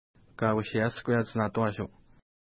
ID: 252 Longitude: -61.6757 Latitude: 56.0708 Pronunciation: ka:u:ʃeja:skweja:ts-na:twa:ʃu: Translation: Wooded Ridge Lake Feature: lake Explanation: Named in reference to nearby mountain Kausheiashkueiat (no 704).